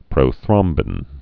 (prō-thrŏmbĭn)